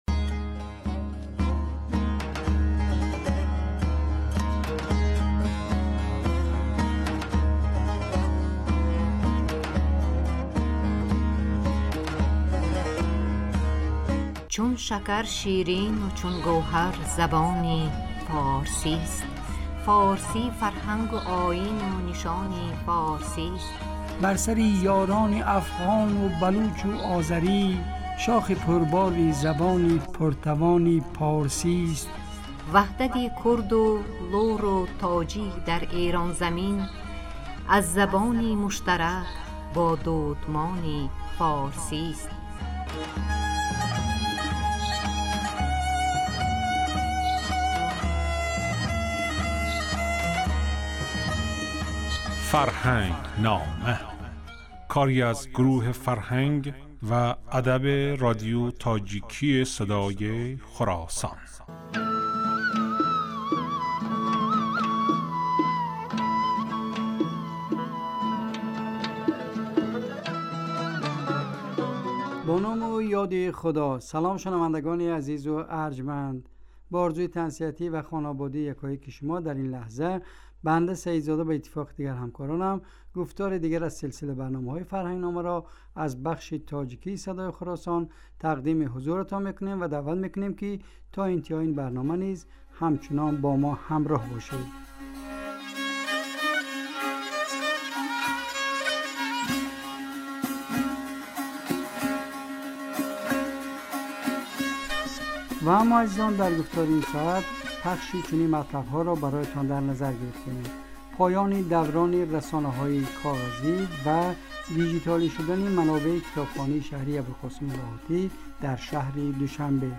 Ин гуфтор ҳар ҳафта рӯзи сешанбе, дар бахши субҳгоҳӣ ва шомгоҳӣ аз Садои Хуросон пахш мегардад.